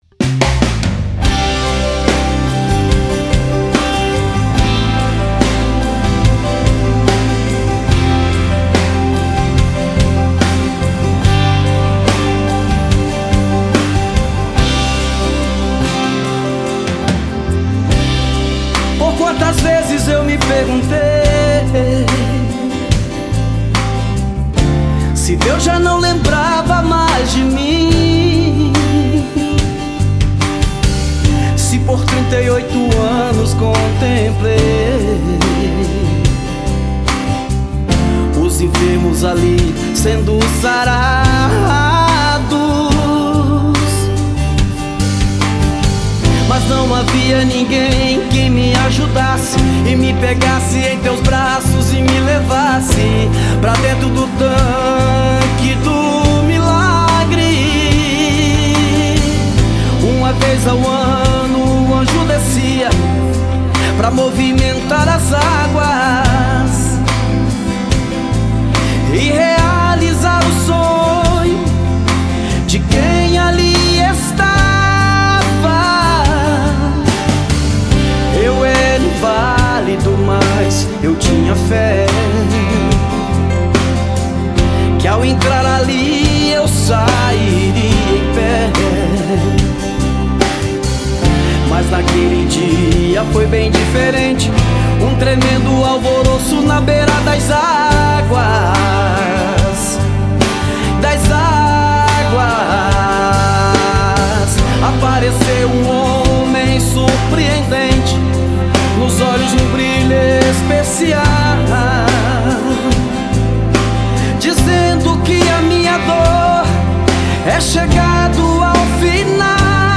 Sertanejo gospel